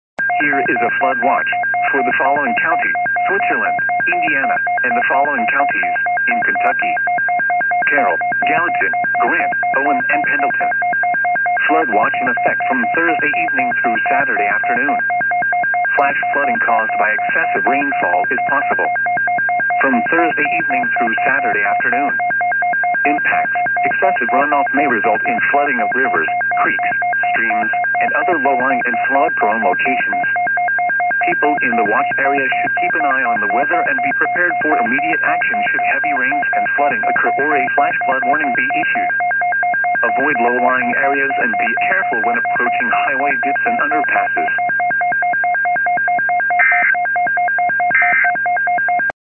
Weather Radio Approach